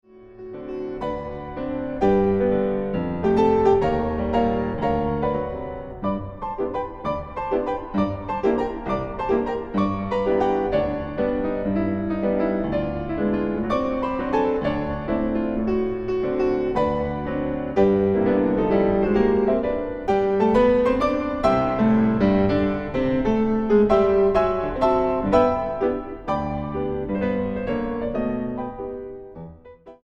pianista